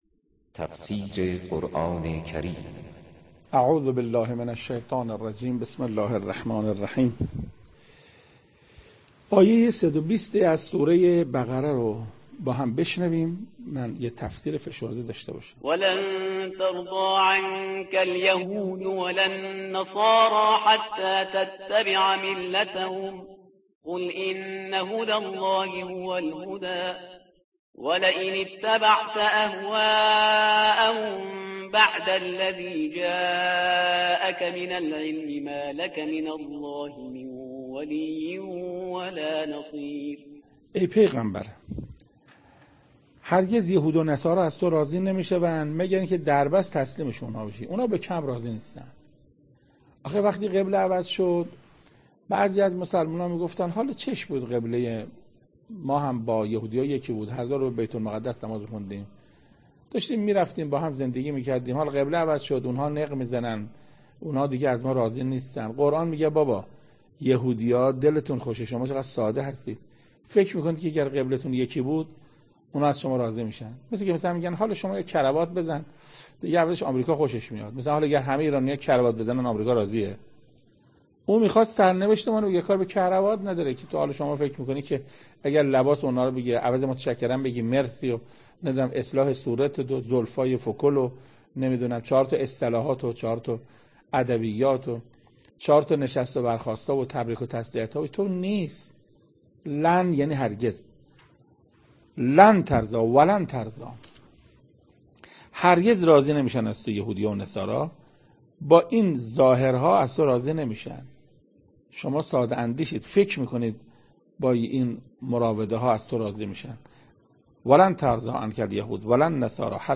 تفسیر صد و بیستمین آیه از سوره مبارکه بقره توسط حجت الاسلام استاد محسن قرائتی به مدت 7 دقیقه